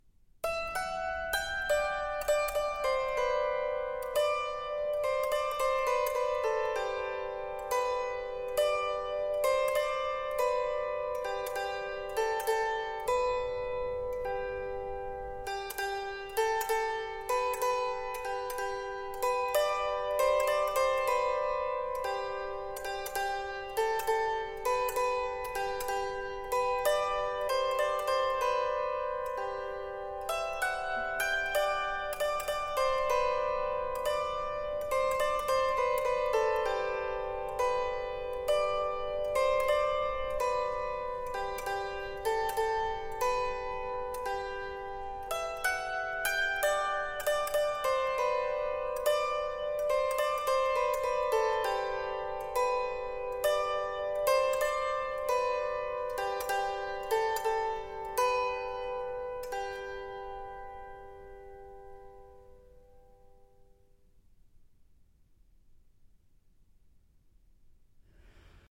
virelai